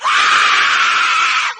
falling_skull2.ogg